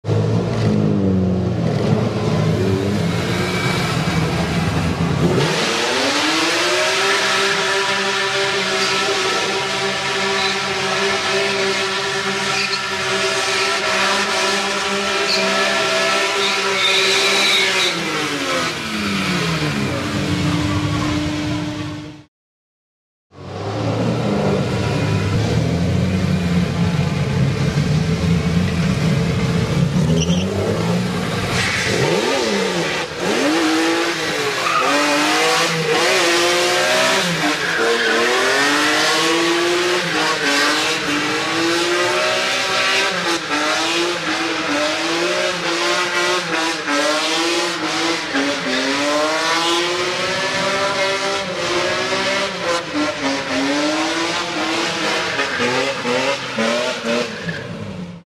Здесь собраны реалистичные записи скольжения автомобилей по разным поверхностям: от асфальтовых треков до сельских грунтовок.
Разные способы дрифта n3.